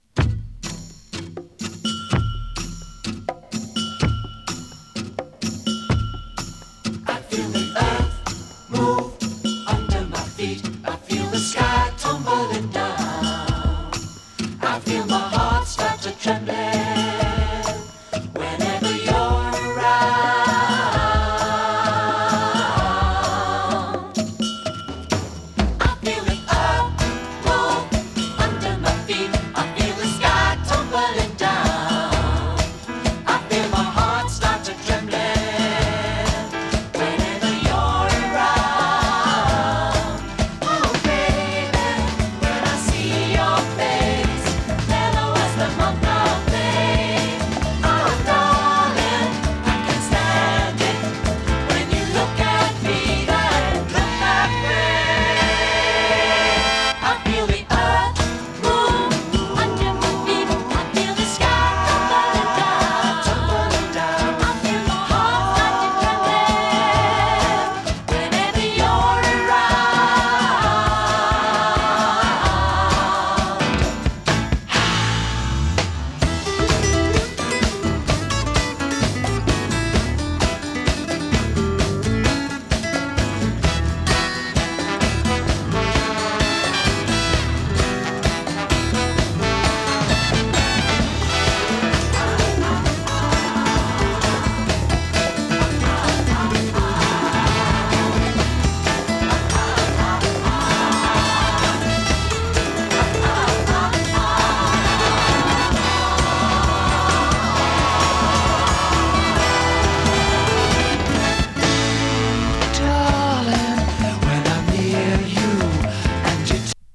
UKの男女混声6人組グループのサードLP。
美しいコーラスにドラマチックでヒネったアレンジが素晴らしい英国ポップです！